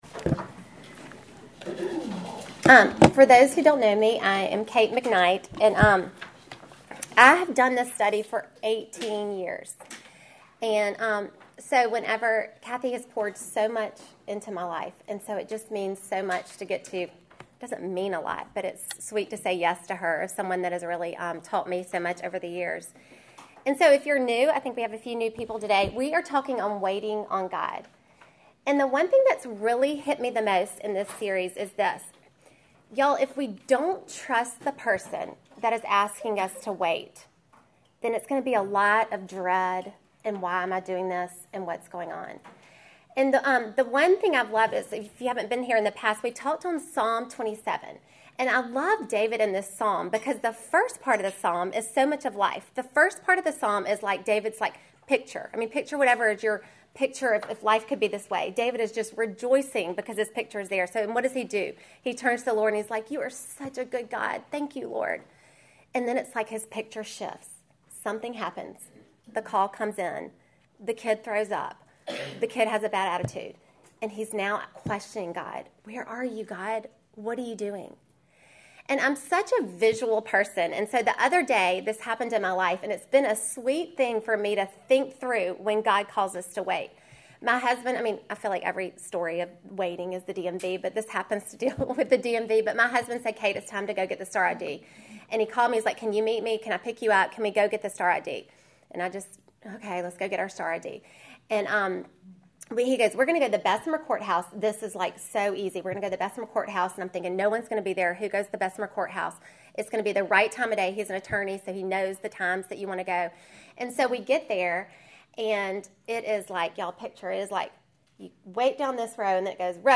Welcome to the fifth lesson in our series WAITING ON GOD!